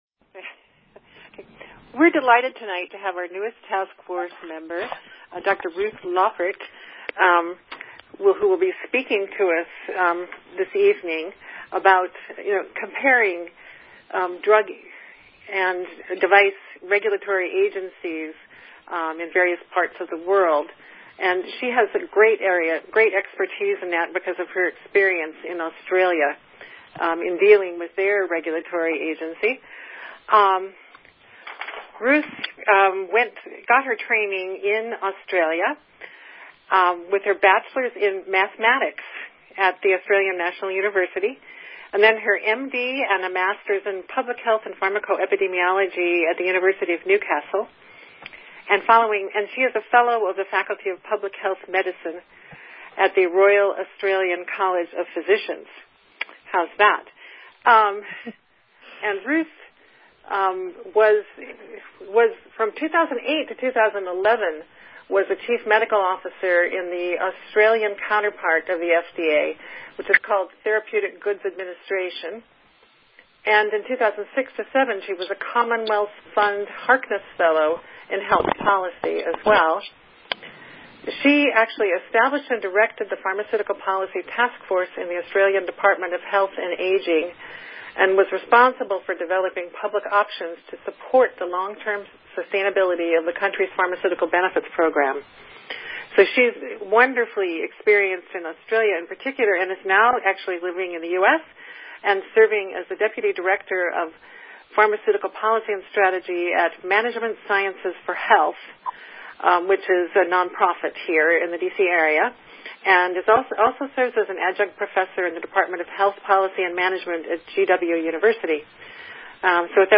NPA FDA Task Force Webinar – Feb 2016